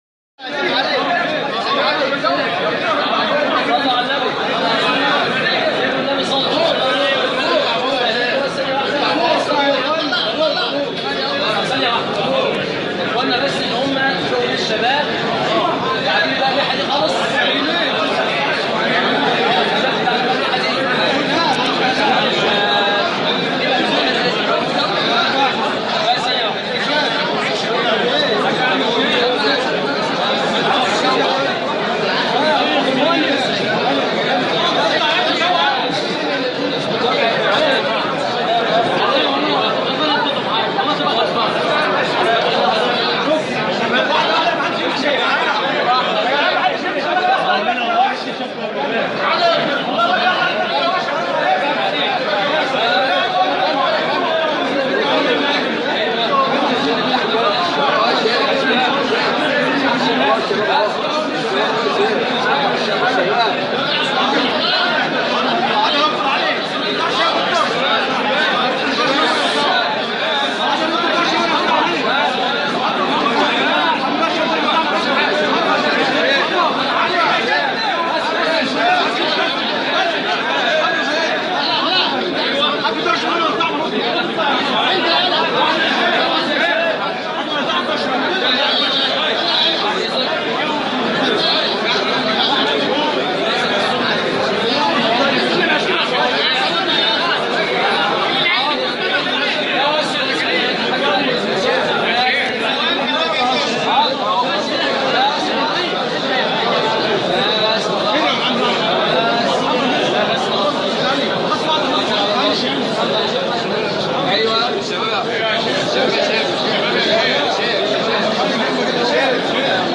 الدعوة الميدانية